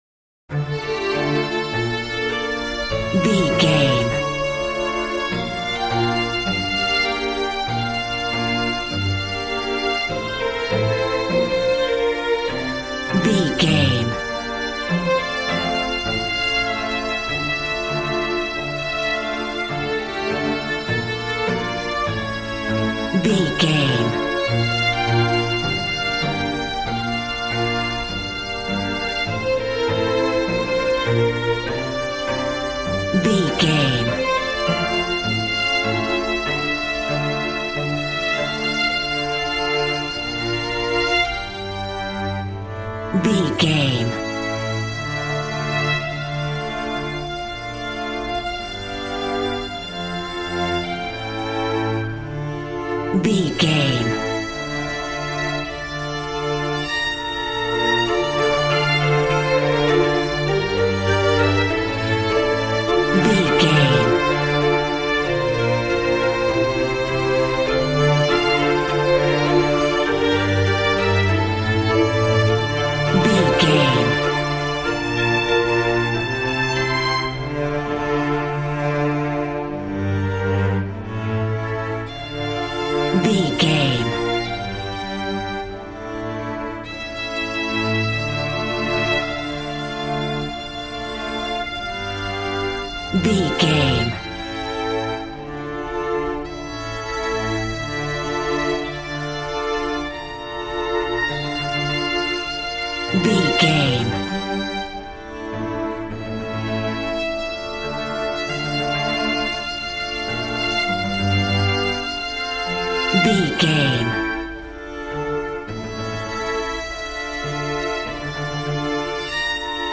Beautiful stunning solo string arrangements.
Regal and romantic, a classy piece of classical music.
Aeolian/Minor
regal
strings
brass